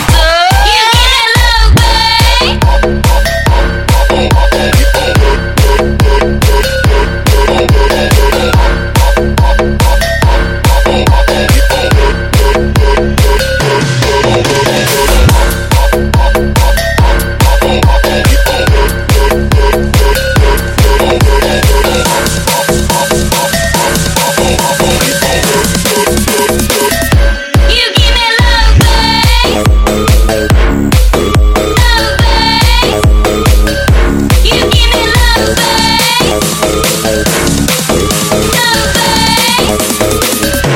Nhạc Chuông DJ - Nonstop